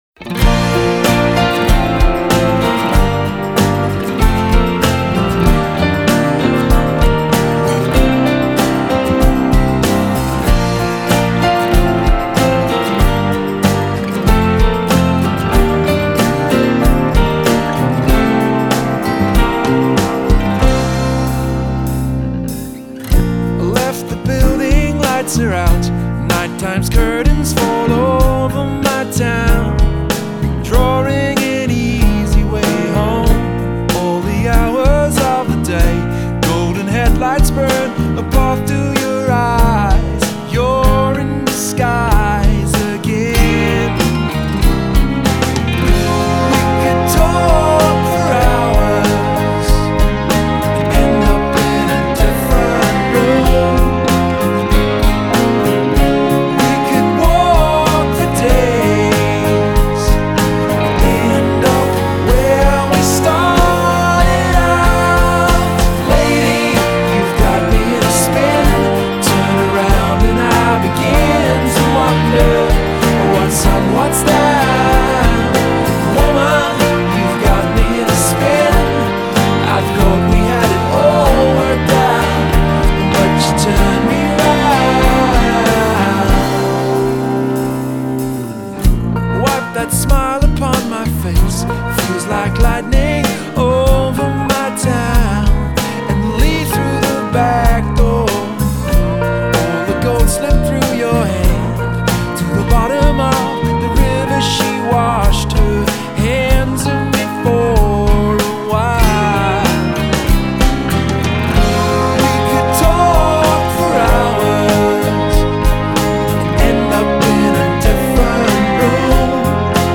melodic, rootsy, contemporary pop music